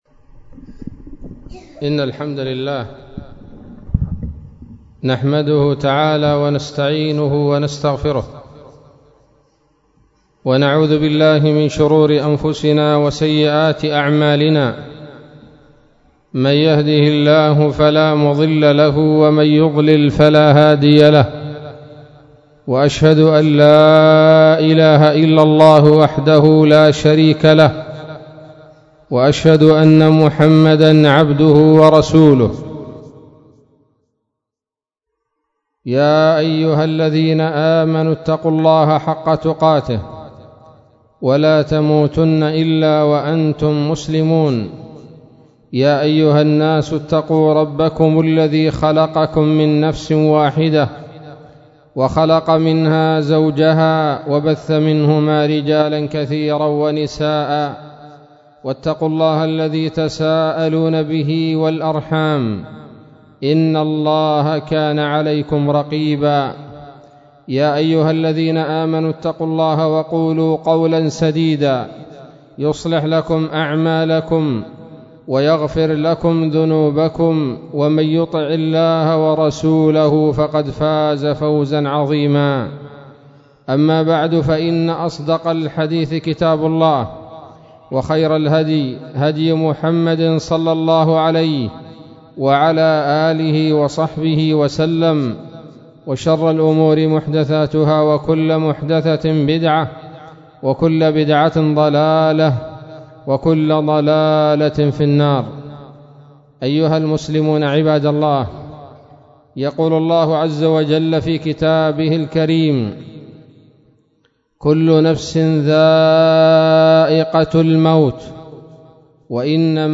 خطبة بعنوان : ((سكــــرات المــــــــــوت)) 27 محرم 1438 هـ